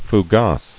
[fou·gasse]